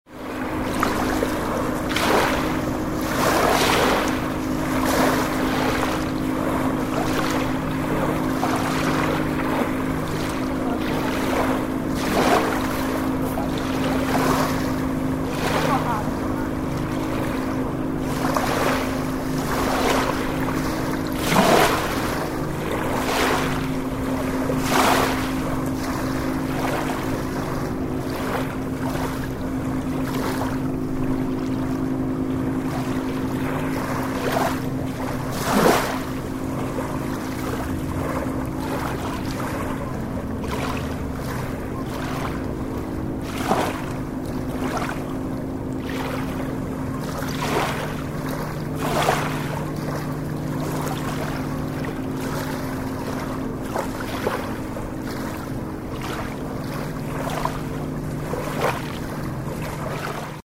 Free beach by the Danube
Field recording of ambient beach sounds, vehicles, people, waves from Zebegény, Hungary